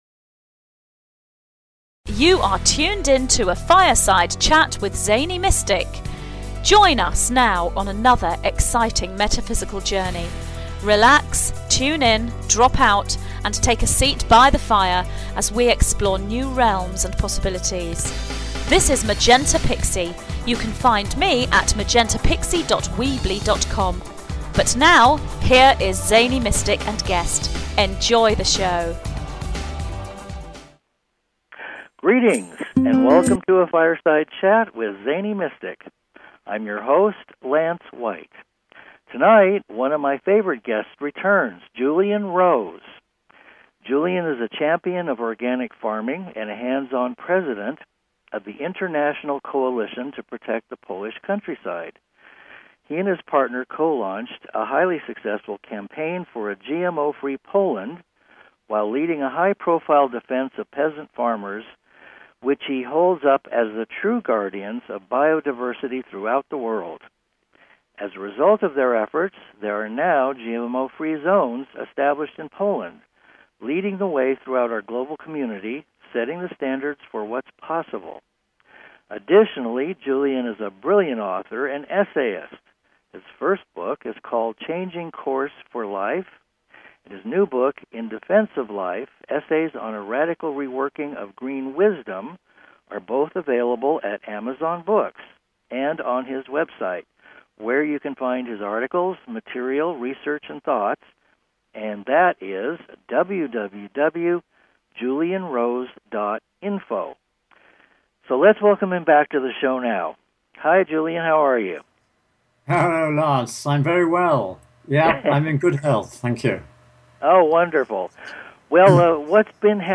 Talk Show Episode, Audio Podcast
This is a "no holds barred" interview, which contains information which may surprise, as it informs.